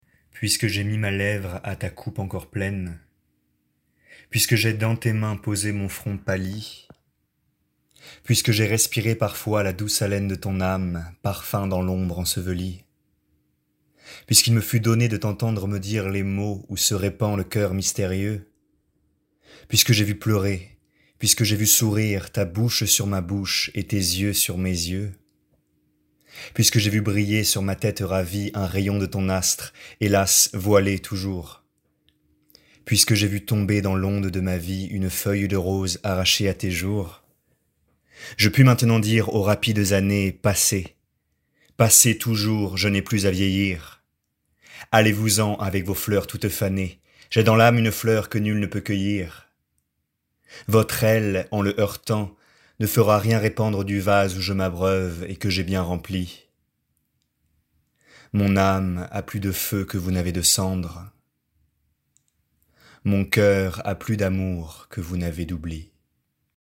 Voix off
20 - 35 ans - Baryton